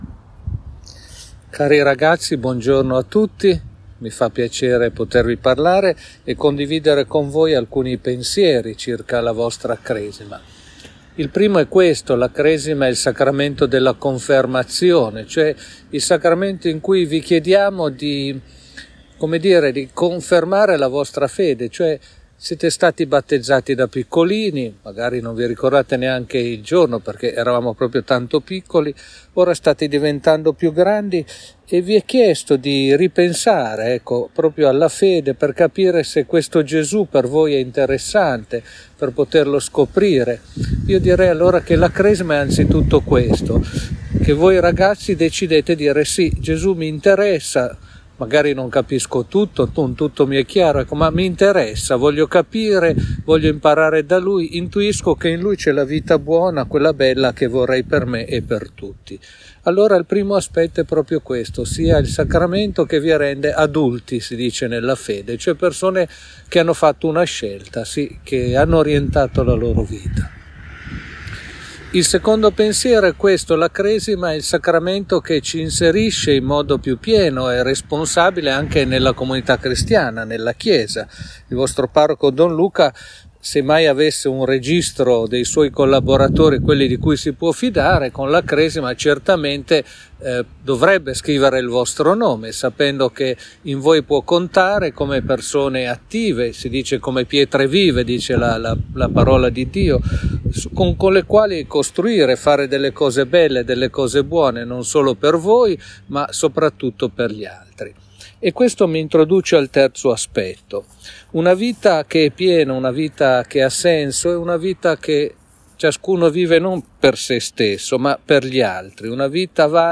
2. Per i ragazzi della Cresima c’è l’ultimo dei 9 audio messaggi per prepararsi ad accogliere i doni dello Spirito Santo, registrato per voi dal vescovo Marco.
Ascolta l’audio del vescovo.